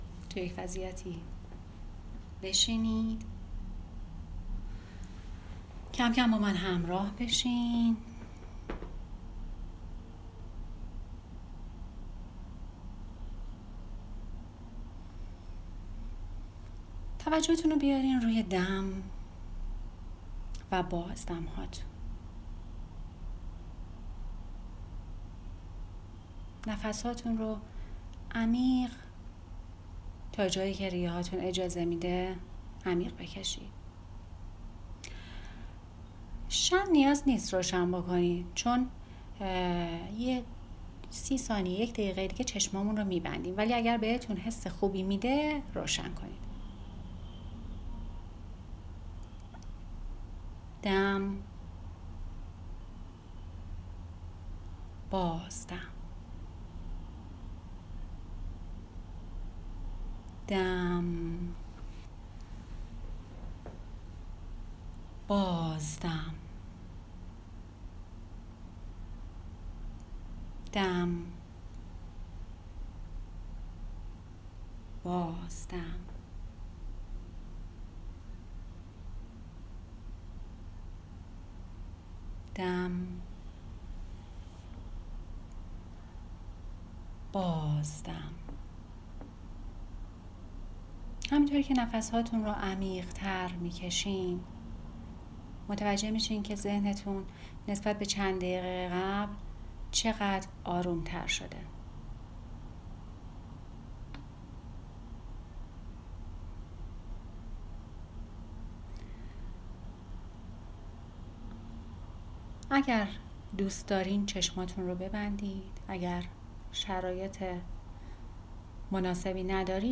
پادکست مراقبه من کیستم؟
مراقبه-من-کیستم-لایو.mp3